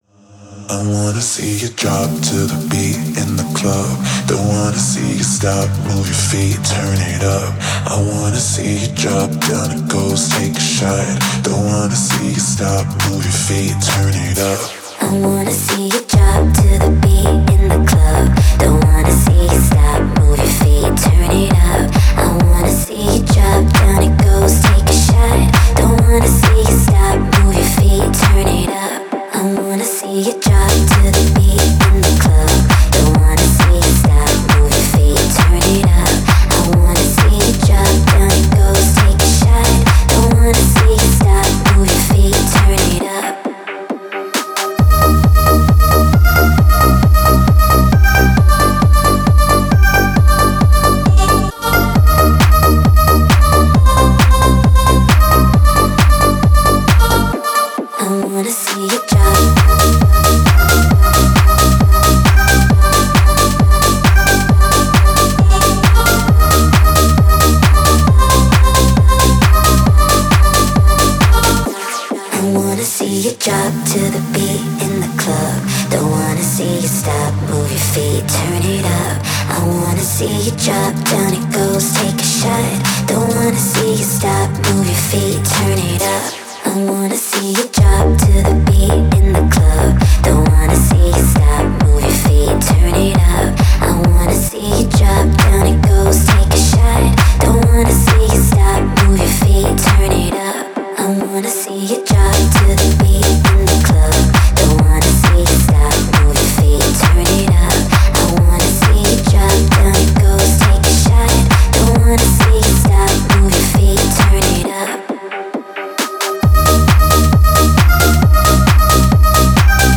Клубная музыка